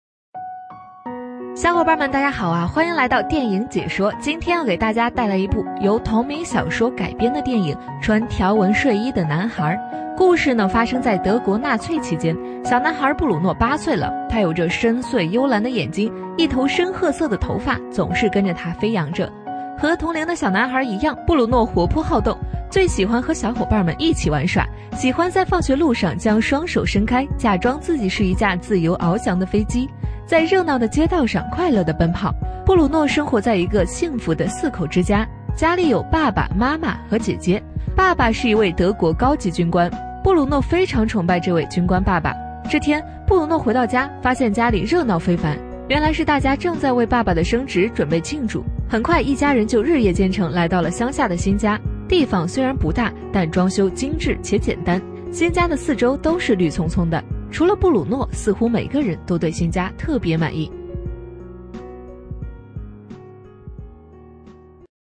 【女57号电影解说】穿条纹睡衣的男孩